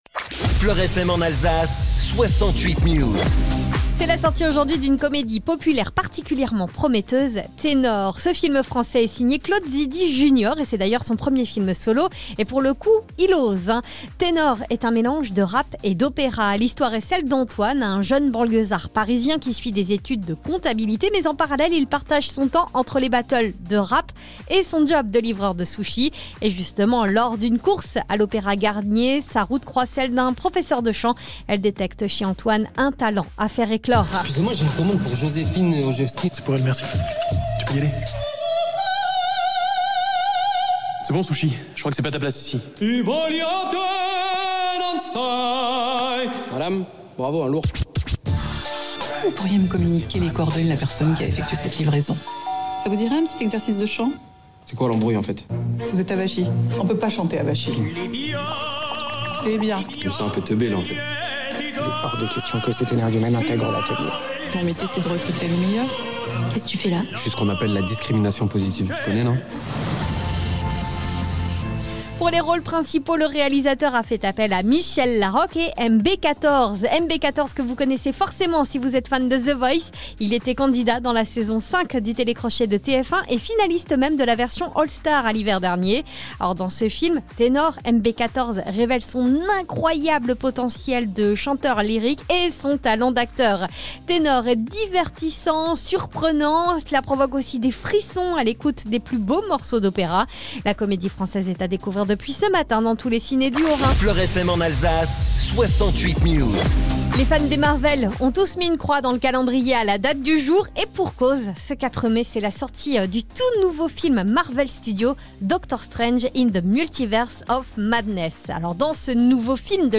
FLOR FM : Réécoutez les flash infos et les différentes chroniques de votre radio⬦